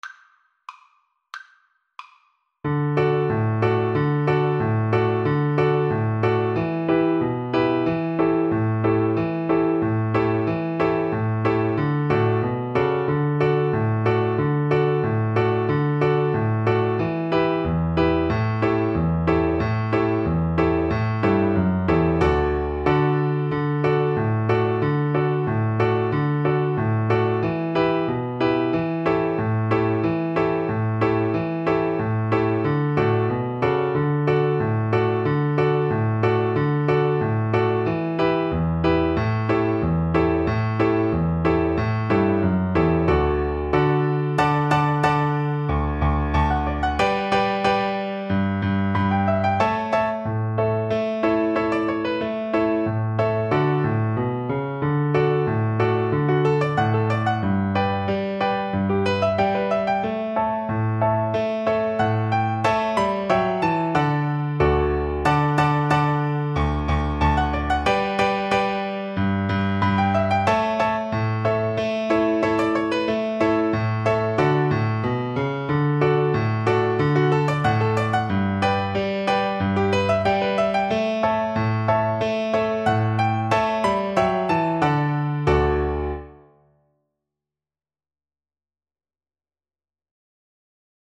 Traditional Music of unknown author.
D major (Sounding Pitch) (View more D major Music for Viola )
2/4 (View more 2/4 Music)
Moderato =c.92